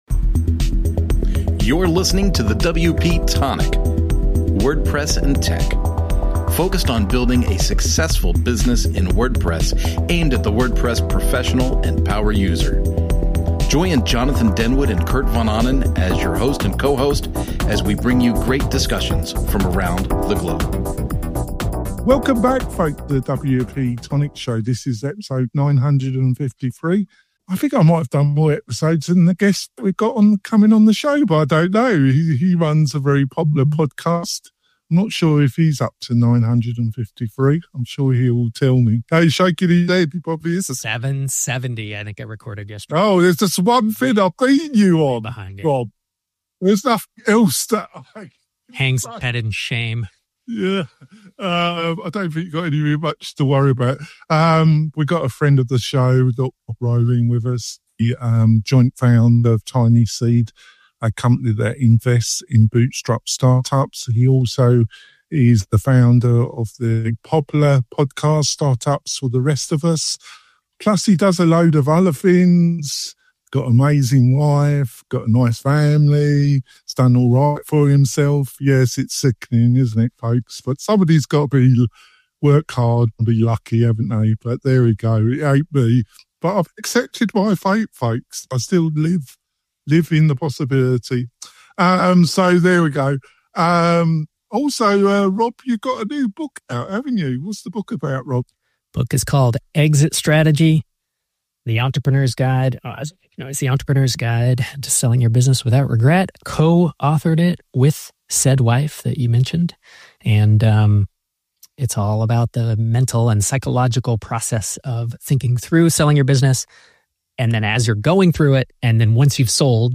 We interview some creative WordPress and startup entrepreneurs plus online experts who, with their insights, can help you build your online business.